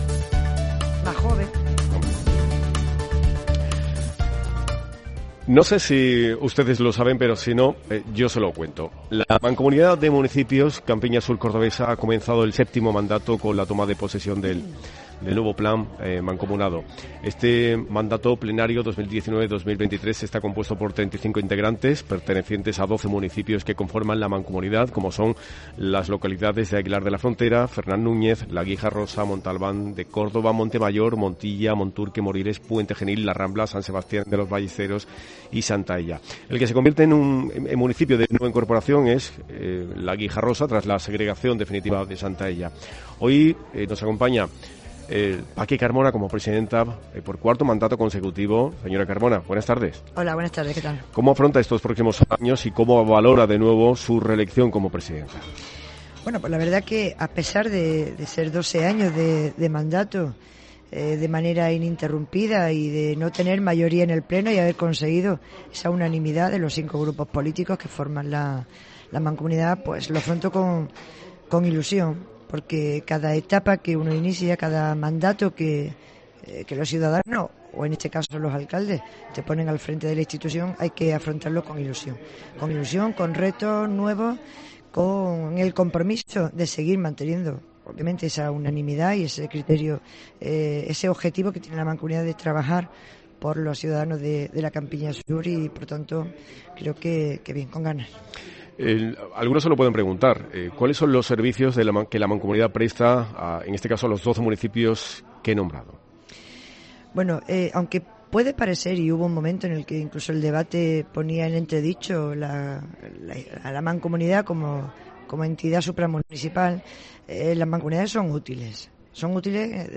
Por los micrófonos de COPE ha pasado la presidenta de la Mancomunidad de Municipios Campiña Sur Cordobesa Francisca Carmona